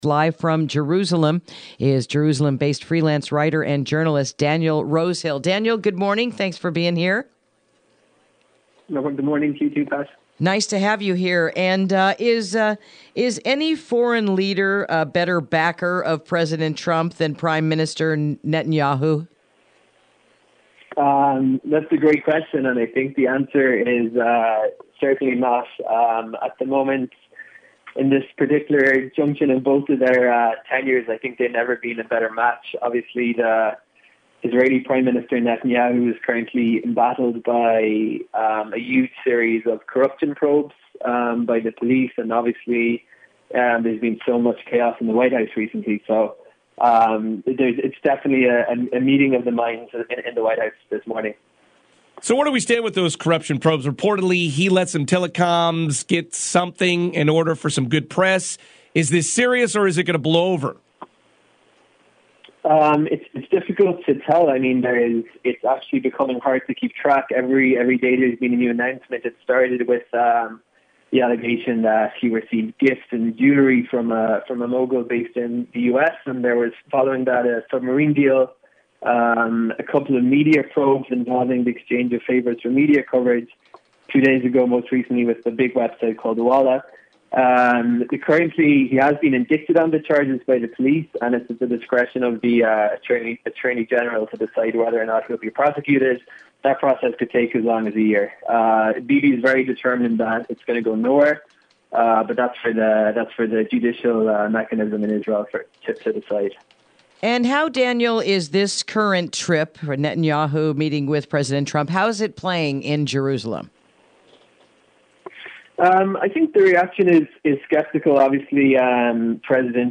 Interview: Everything Surrounding the Talks Between Netanyahu and Trump